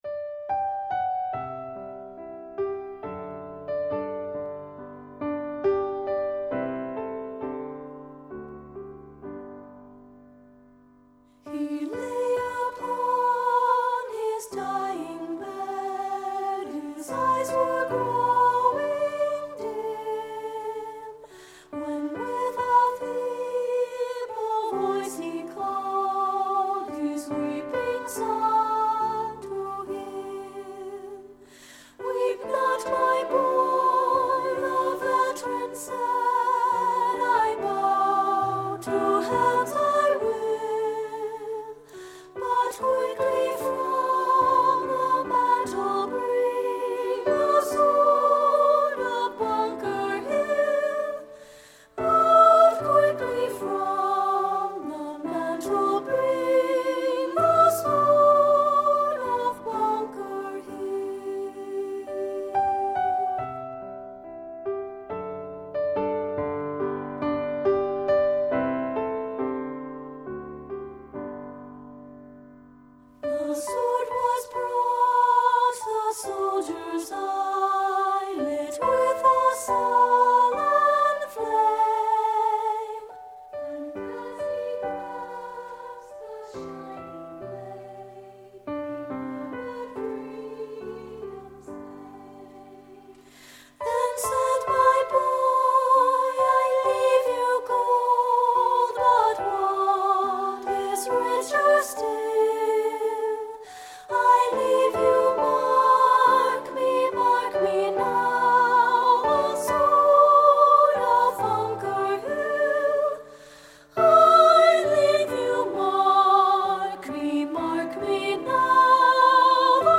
Composer: Civil War Song
Voicing: SSA